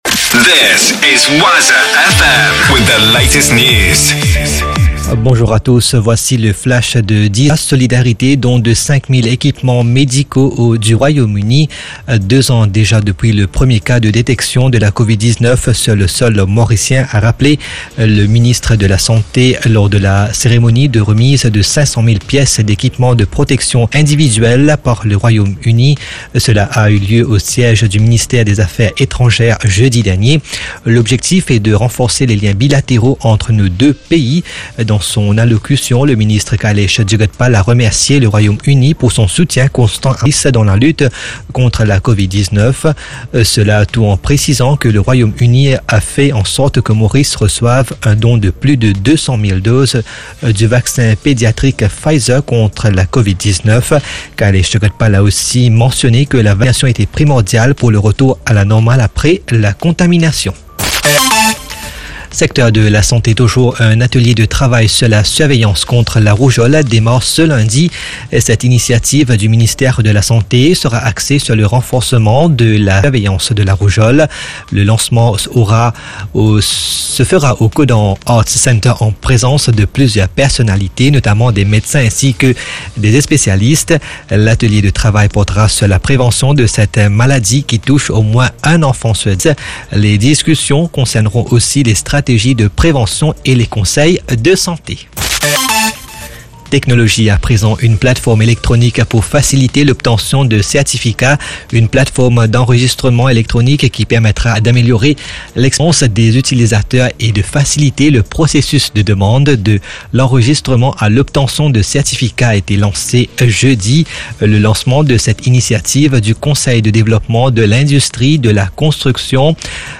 News 29.10.2022 10HR